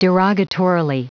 Prononciation du mot derogatorily en anglais (fichier audio)
Prononciation du mot : derogatorily